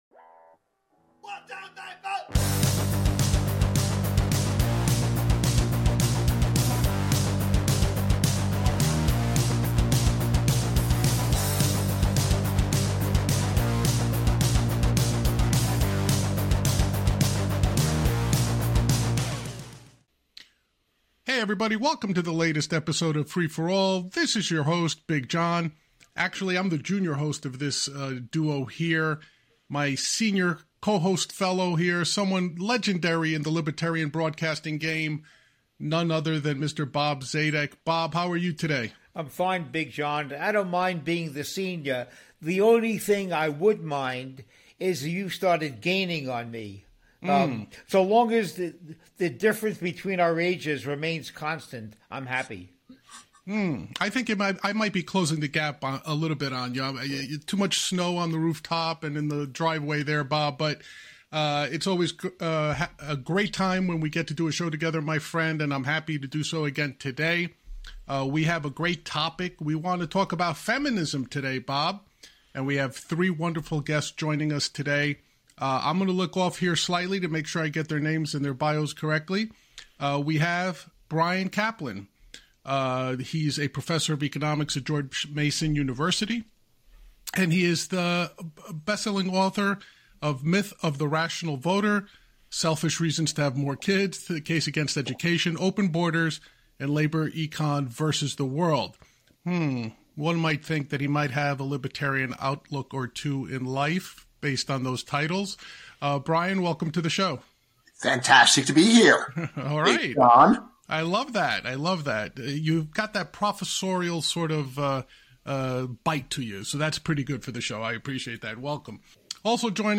Free For All – Feminism Panel Discussion - Grumblings Media